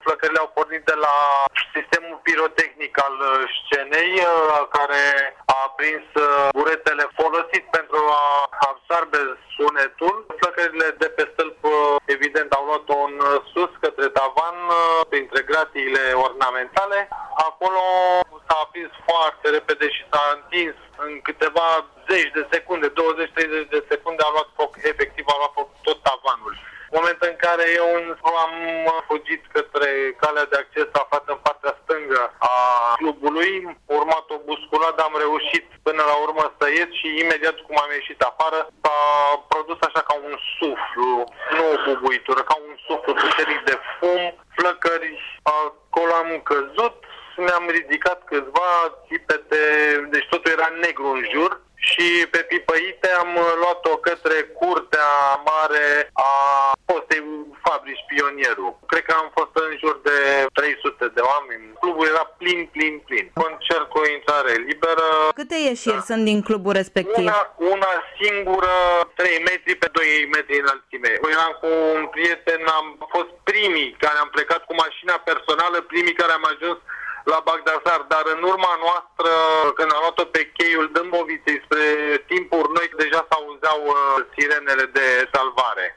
”Totul era negru în jur”, declară în exclusivitate pentru BucureștiFM un martor al tragediei din clubul Colectiv.
declaratie-martor.mp3